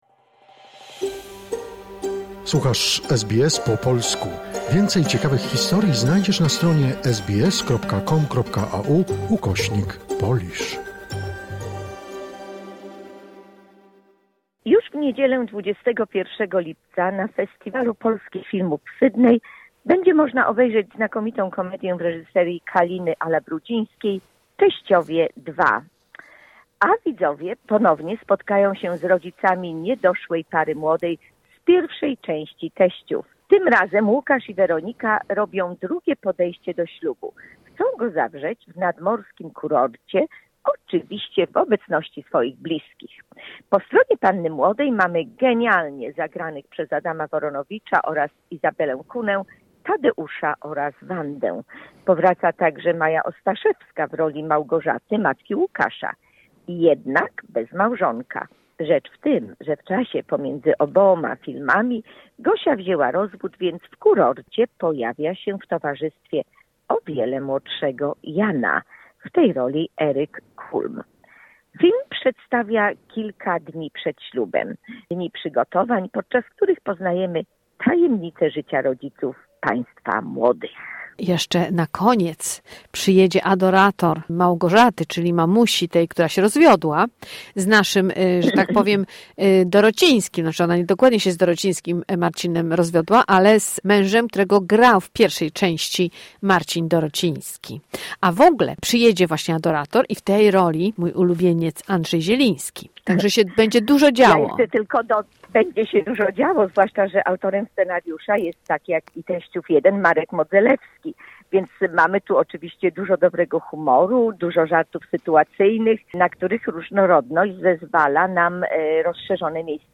"Teściowie 2" - recenzja filmowa